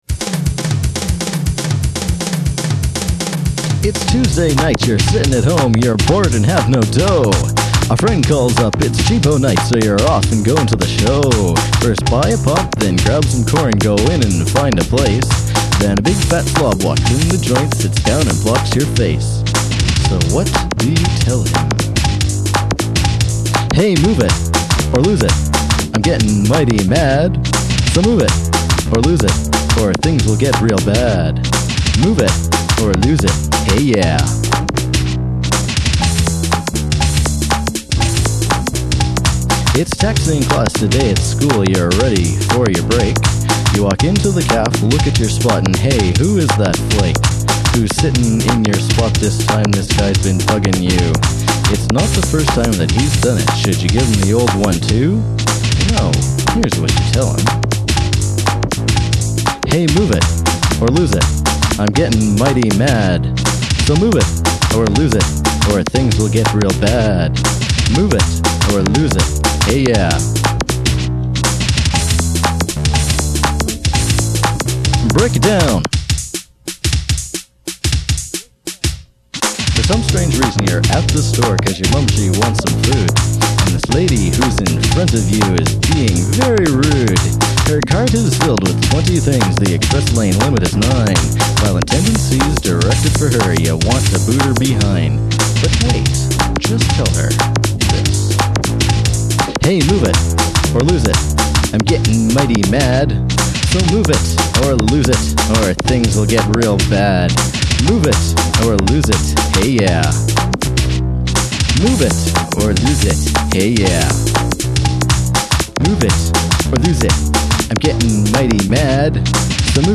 I don’t know if we actually programmed it, or just used a bunch of the built-in beats. My contribution was the bass line, all three notes of it.
rap/radio announcer voice – it’s surprisingly thick and in-your-face, especially right at the beginning.